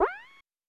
Super Smash Bros. game sound effects
Jump 2.wav